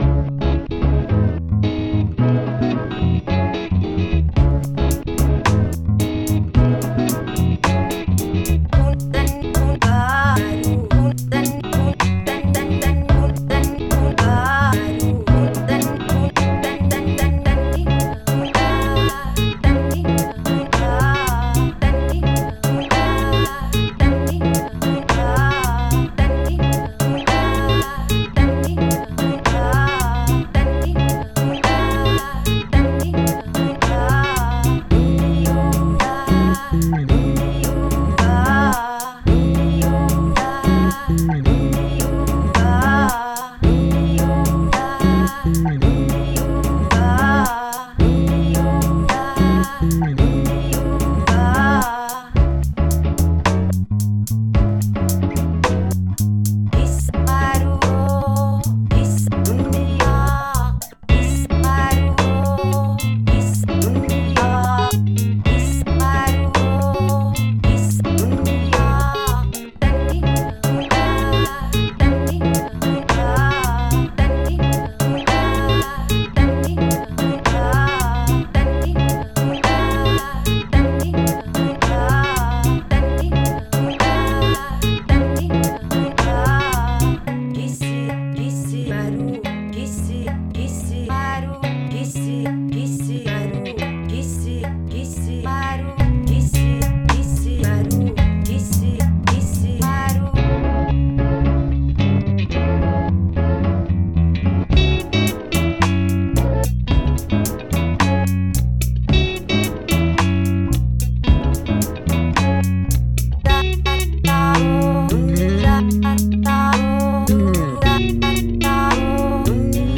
was sampled and played over an acoustic song